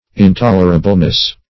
-- In*tol"er*a*ble*ness, n. -- In*tol"er*a*bly, adv.